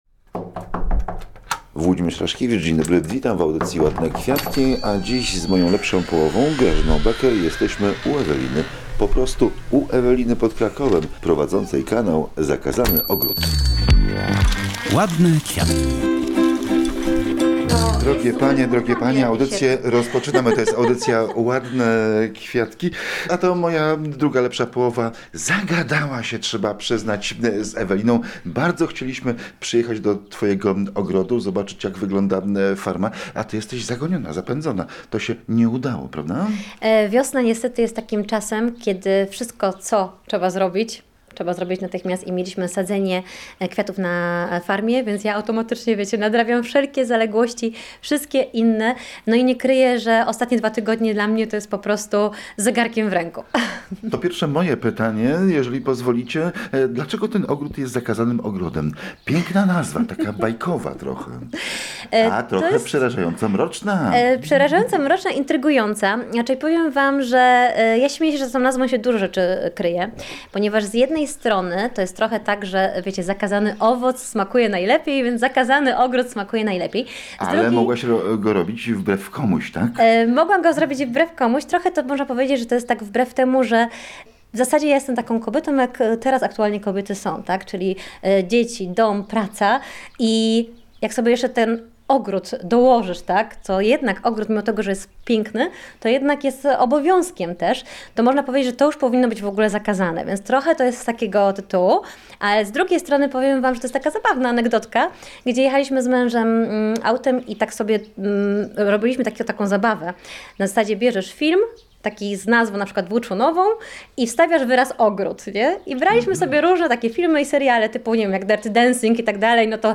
W audycji głównym tematem są wracające do łask, kwiaty z ogródków babcinych. Zapraszamy do posłuchania rozmowy, a Zakazany Ogród znajdziecie >>> TUTAJ .